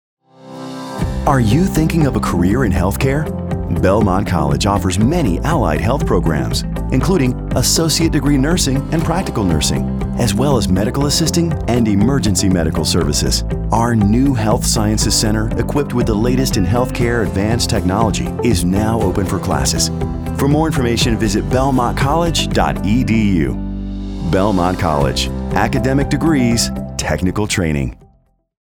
Sincere, Attractive